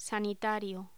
Locución: Sanitario
voz
Sonidos: Voz humana